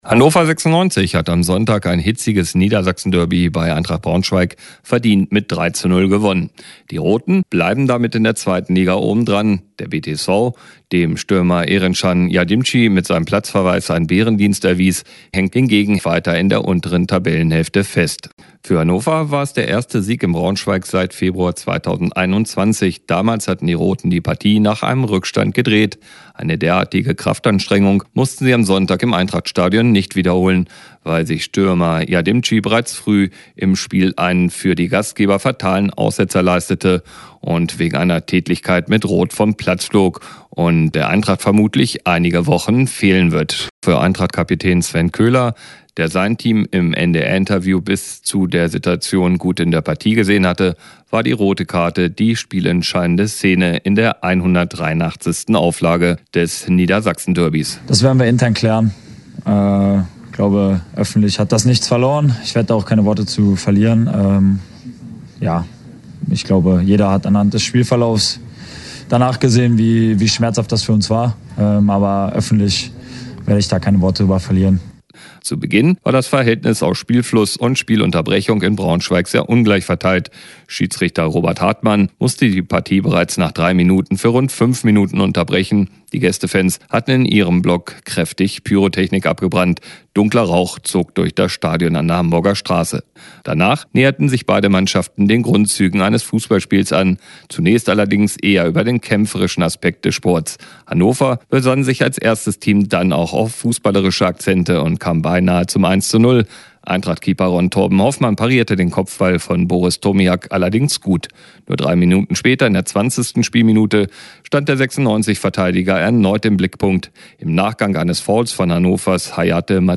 Und dann war da noch das Duell der ewigen Rivalen in Liga zwei.